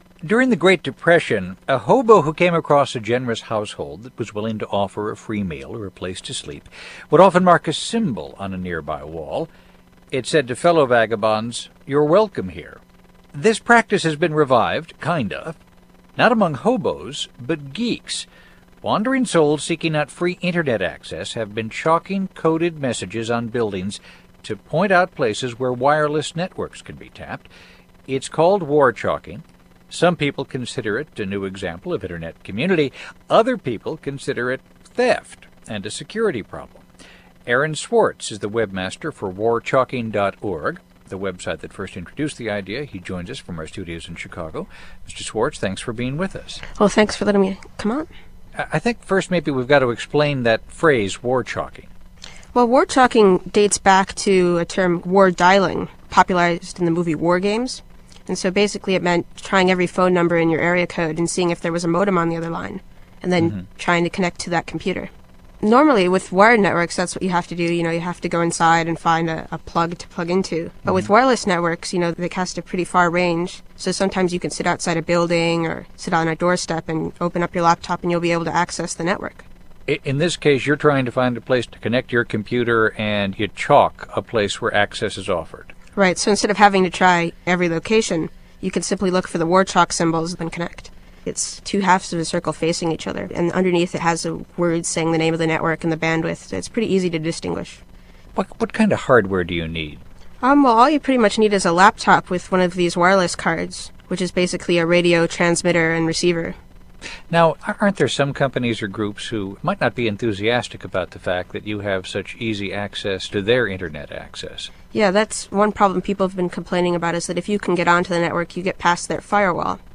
Aaron on the Radio [updated] (Aaron Swartz: The Weblog)
Aaron Swartz, defender of warchalking, was on the BBC World Service’s NewsHour (1.8MB Ogg, 3.2MB MP3) and NPR’s Weekend Edition (abstract, 1.7MB Ogg,